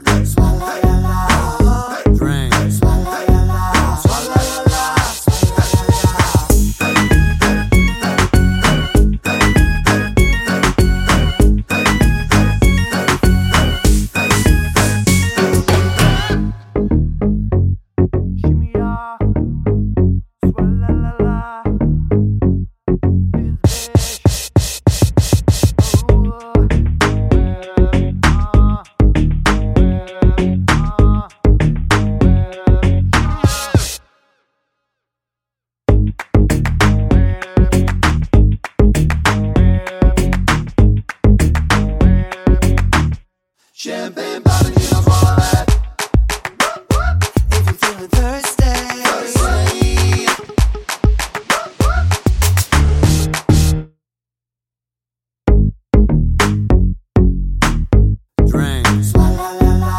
for duet R'n'B / Hip Hop 3:37 Buy £1.50